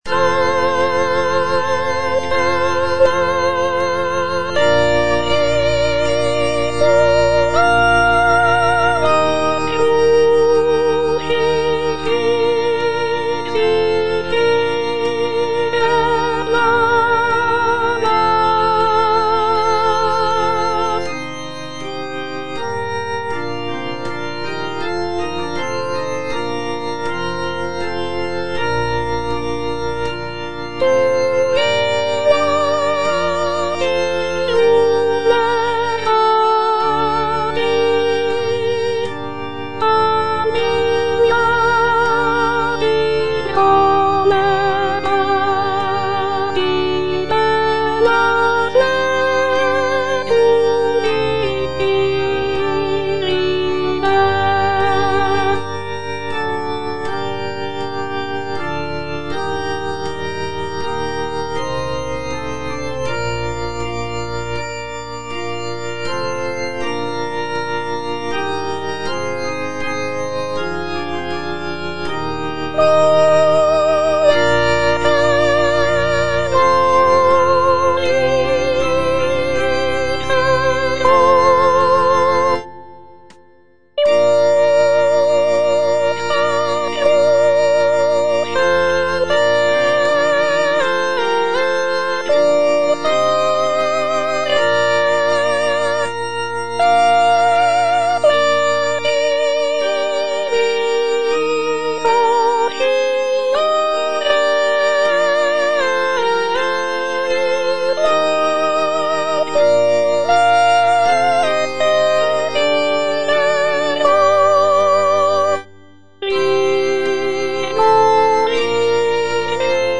(soprano I) (Voice with metronome) Ads stop
sacred choral work